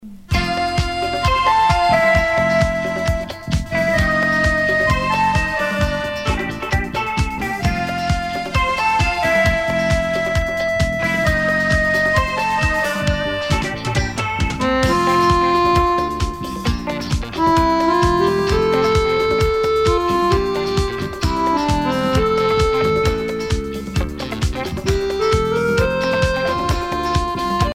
Usage d'après l'analyste gestuel : danse ;
Catégorie Pièce musicale éditée